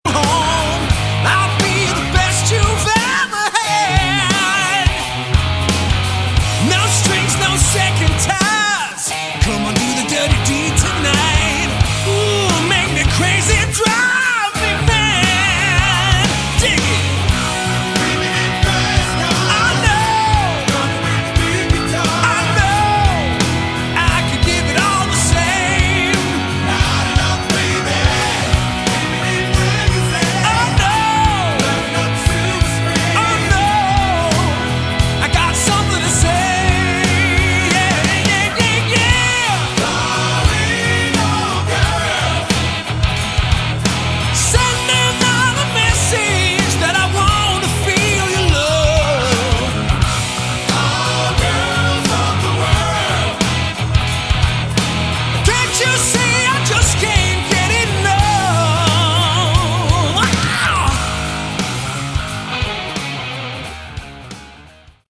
Category: Hard Rock
Vocals, Keys
Drums
Bass
Guitars